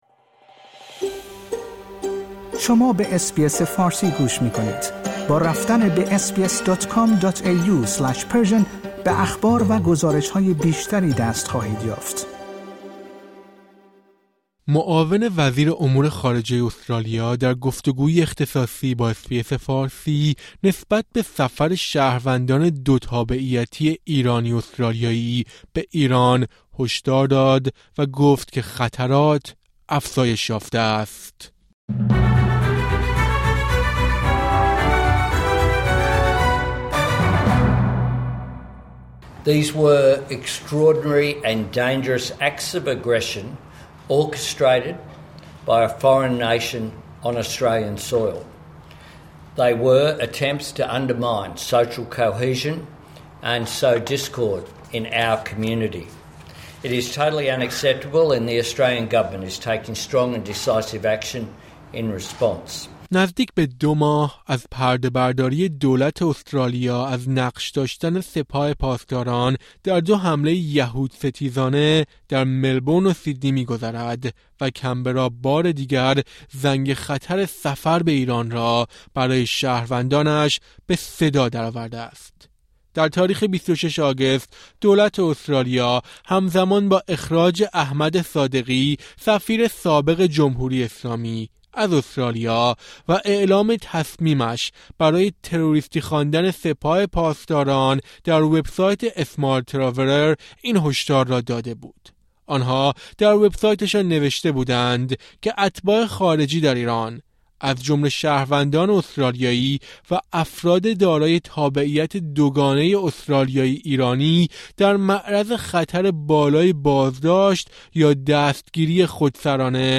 معاون وزیر امور خارجه استرالیا در گفت‌وگویی اختصاصی با اس‌بی‌اس فارسی، نسبت به سفر شهروندان دوتابعیتی ایرانی-استرالیایی به ایران هشدار داد و گفت که «خطرات افزایش یافته است».